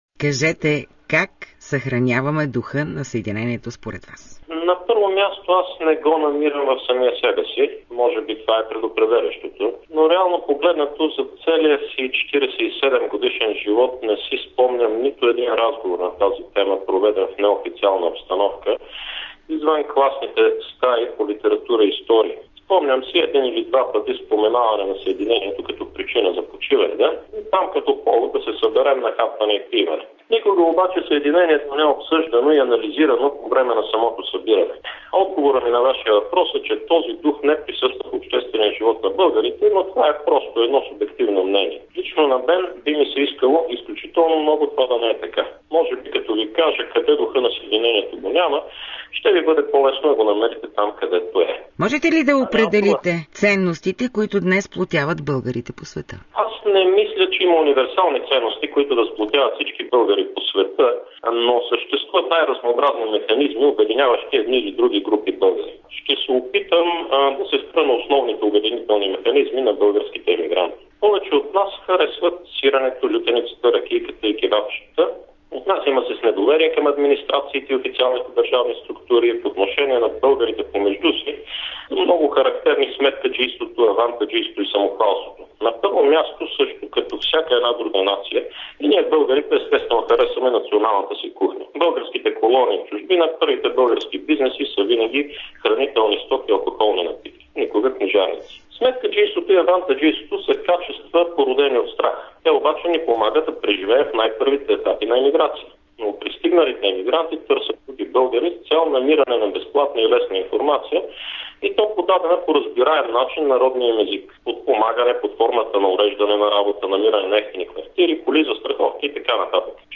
Историческа ретроспекция по повод Деня на Съединението, направена от журналистката от Радио България